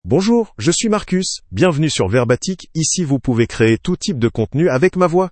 Marcus — Male French (France) AI Voice | TTS, Voice Cloning & Video | Verbatik AI
MaleFrench (France)
Marcus is a male AI voice for French (France).
Voice sample
Listen to Marcus's male French voice.
Marcus delivers clear pronunciation with authentic France French intonation, making your content sound professionally produced.